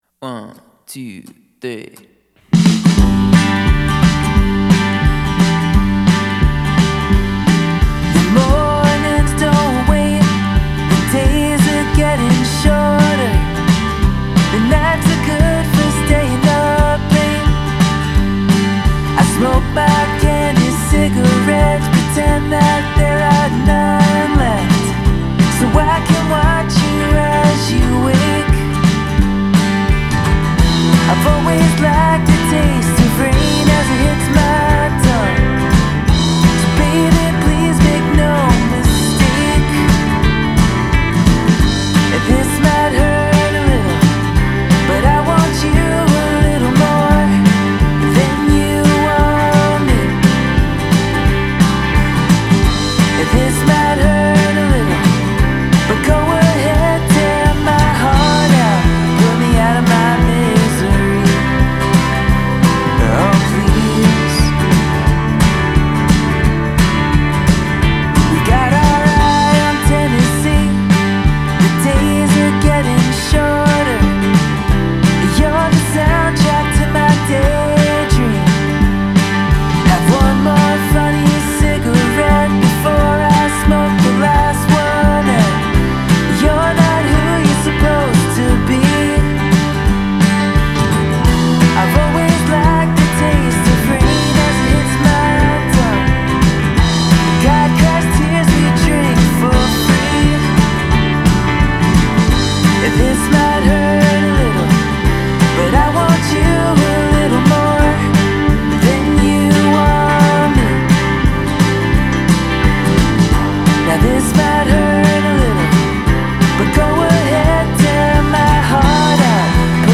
in a bright, breezy and melodic way